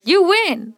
YouWin.wav